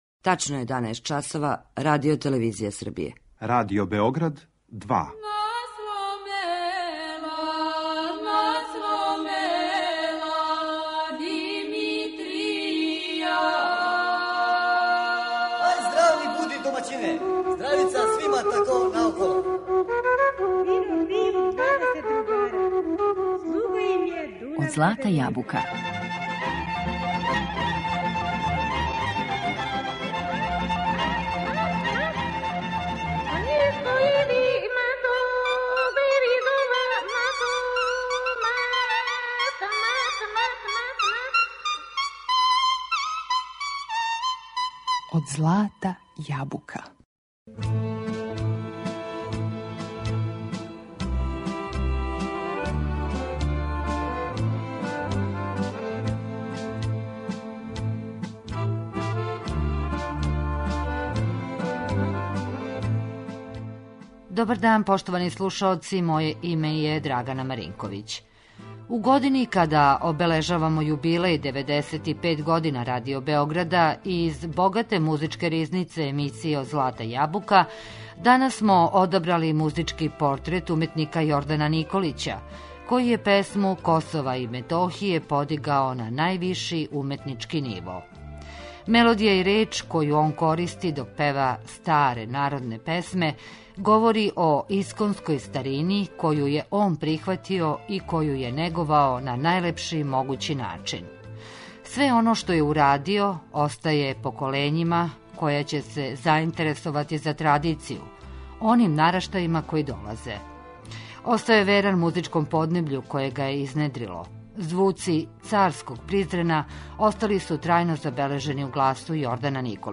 Мелодијe и речи којe он користи док пева старе народне песме говорe о исконској старини коју је прихватио и коју је неговао на најлепши начин.